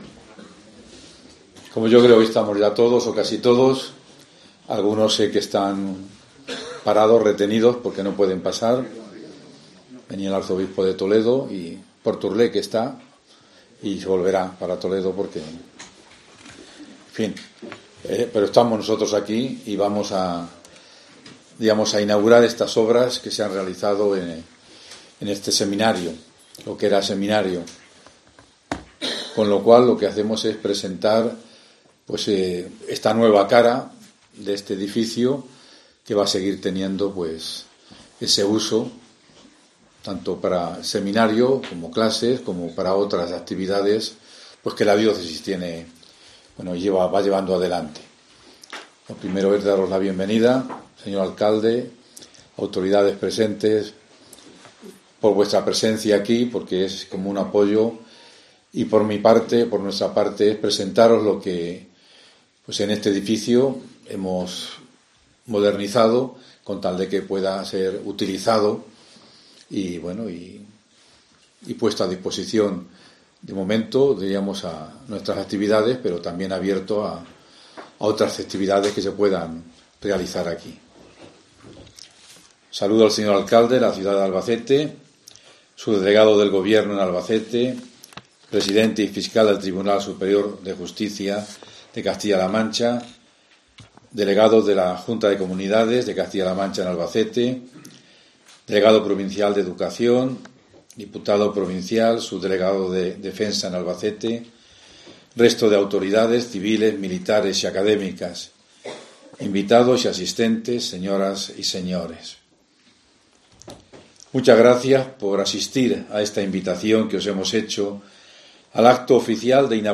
Discurso de don Ángel Fernández Collado en la inauguración de la reforma del Seminario